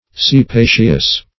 Search Result for " cepaceous" : The Collaborative International Dictionary of English v.0.48: Cepaceous \Ce*pa"ceous\, a. [L. cepa, caepa, onion.] Of the nature of an onion, as in odor; alliaceous.
cepaceous.mp3